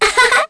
Kara-Vox_Happy2_kr.wav